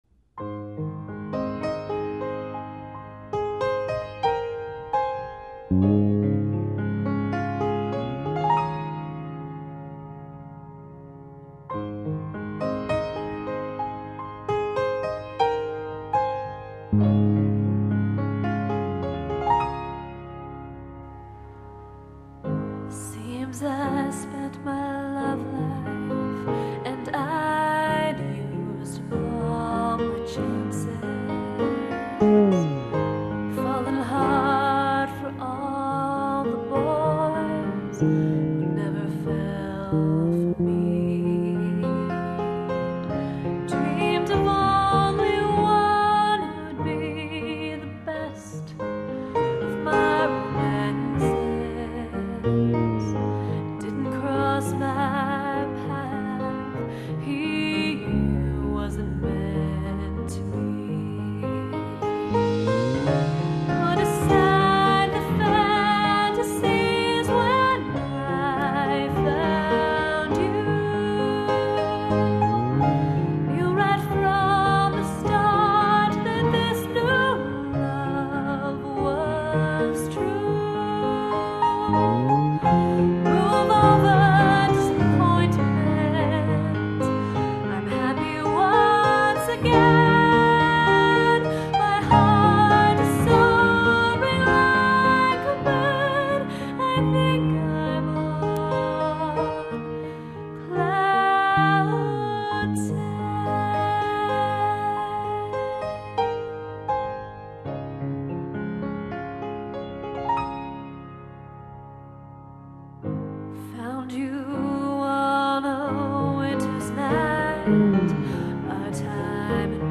Genre: Songwriter (Multi-Genre and multiple vocalists)